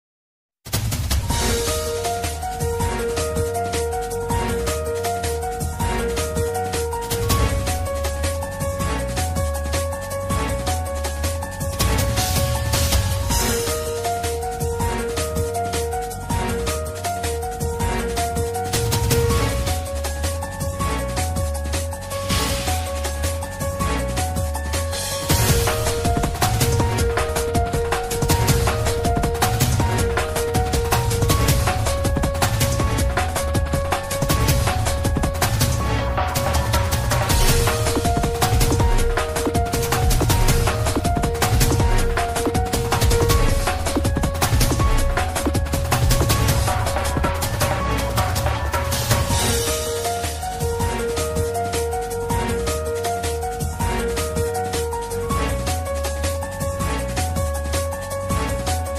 sonido de drama